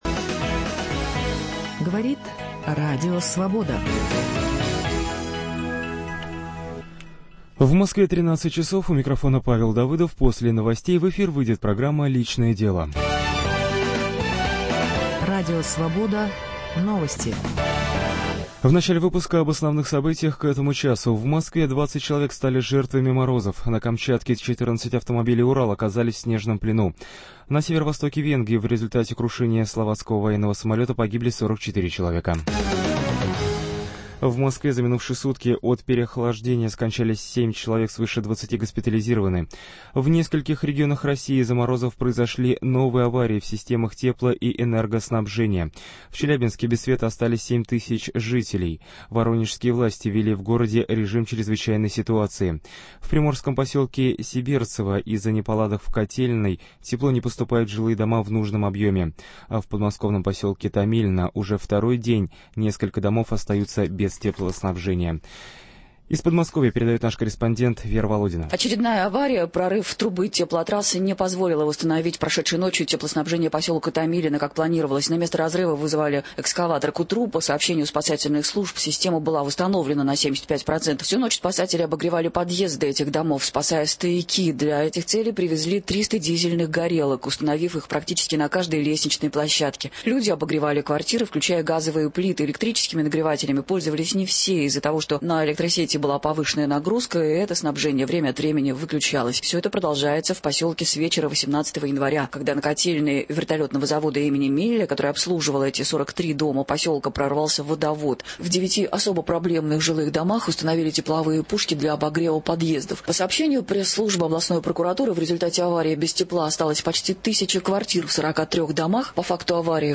Утром в газете, а с часу дня в прямом эфире - обсуждение самых заметных публикации российской и зарубежной печати. Их авторы и герои - вместе со слушателями.